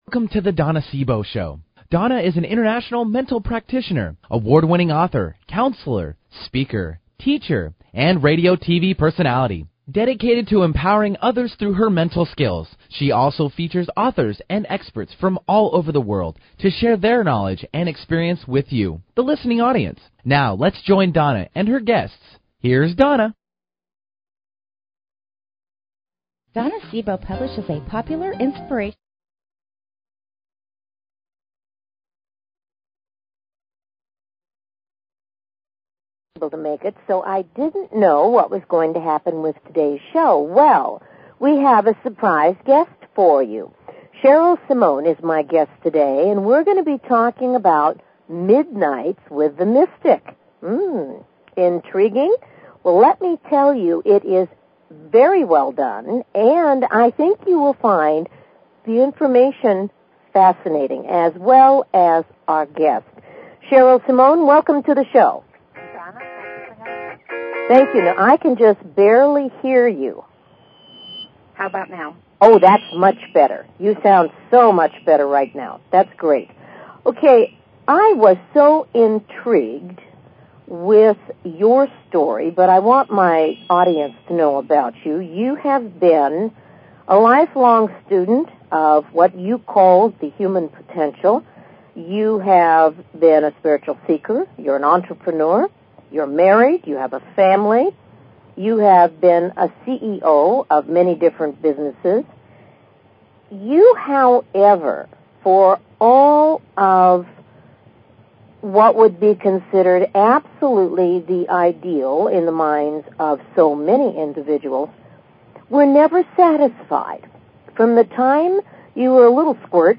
Talk Show Episode
Today will be an open mike hour so you can call in at any time to have a 'free' reading with me on the air.
Callers are welcome to call in for a live on air psychic reading during the second half hour of each show.